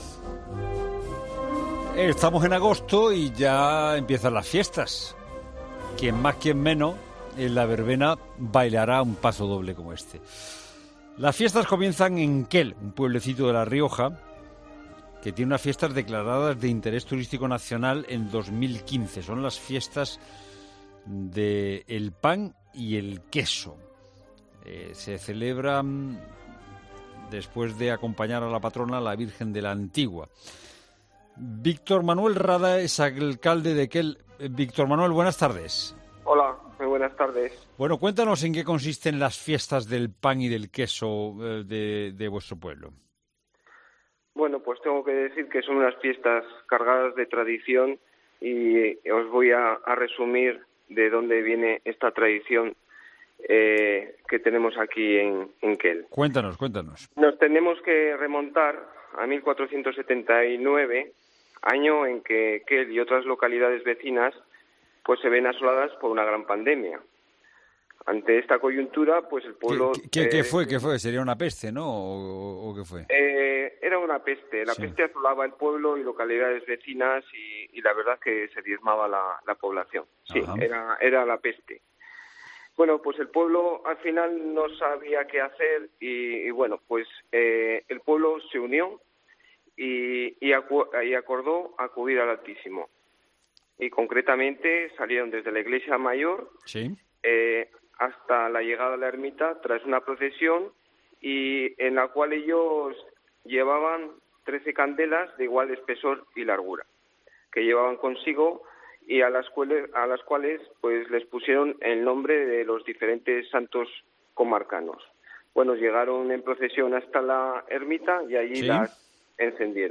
Este lunes en 'La Tarde' en COPE, el alcalde de la localidad, Víctor Manuel Rada, explica a qué año se remonta esta celebración y cómo se lleva a cabo.